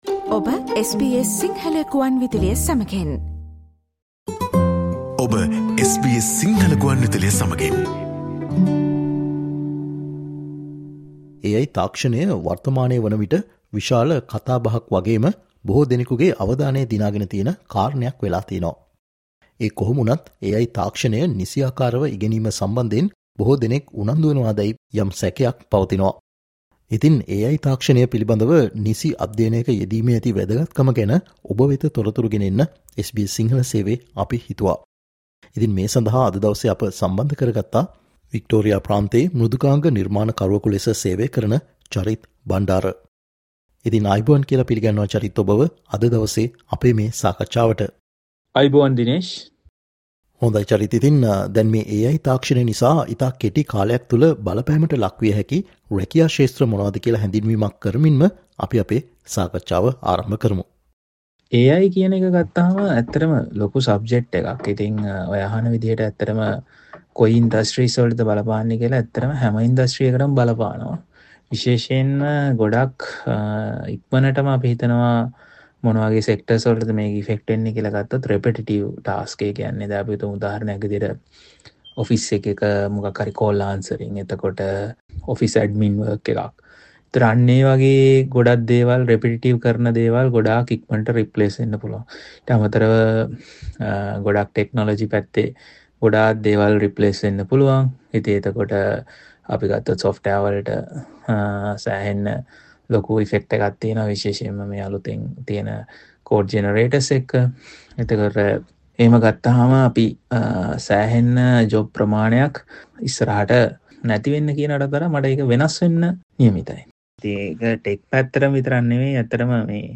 SBS සිංහල සේවය සිදුකල සාකච්චාව.